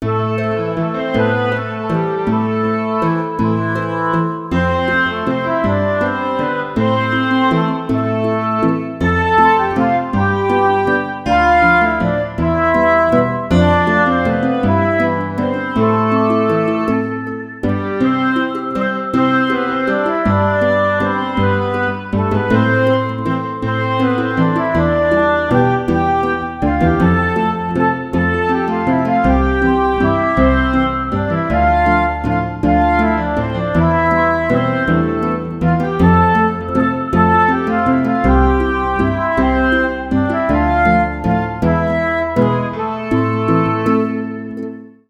Vals lento 2. Forma binaria.
vals
tristeza
ternario
lento
melancólico
menor
Sonidos: Música